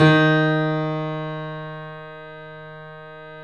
Index of /90_sSampleCDs/E-MU Producer Series Vol. 5 – 3-D Audio Collection/3D Pianos/YamaMediumVF04